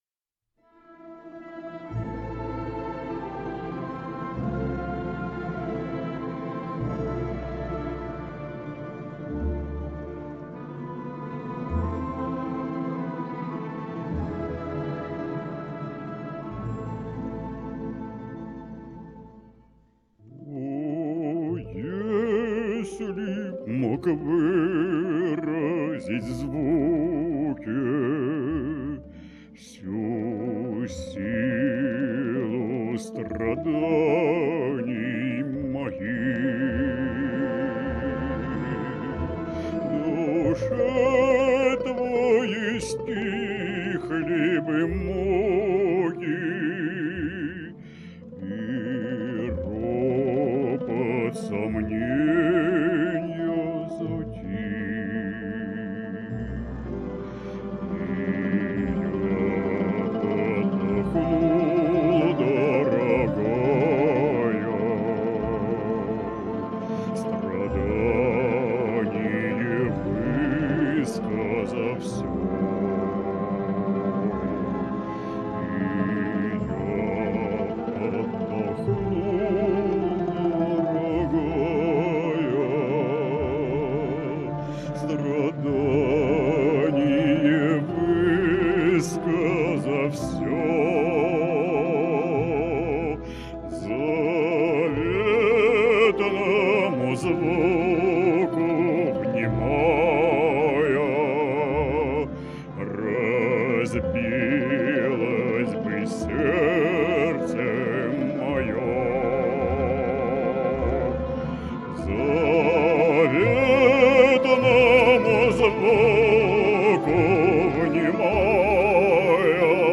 какая глубина и теплота звучания.....